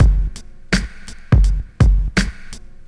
• 83 Bpm '00s Drum Loop Sample G Key.wav
Free drum loop sample - kick tuned to the G note.
83-bpm-00s-drum-loop-sample-g-key-Oqs.wav